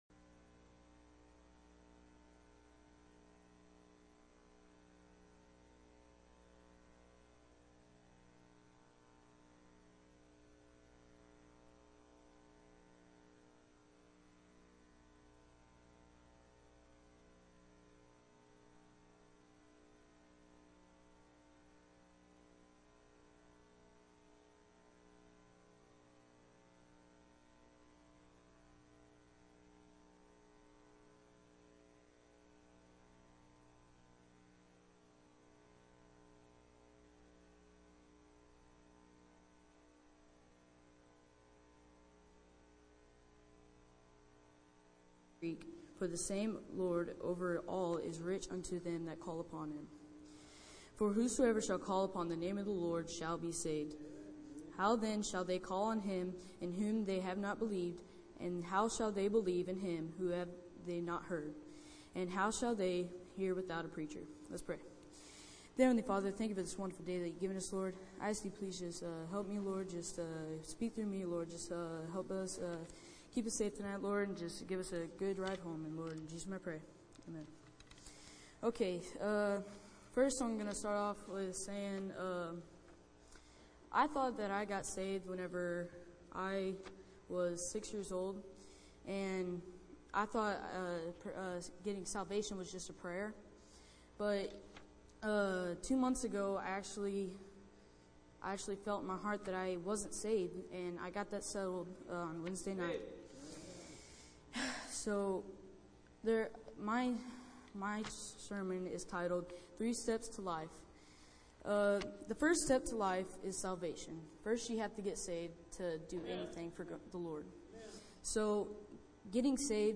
110517PM - Youth Led Service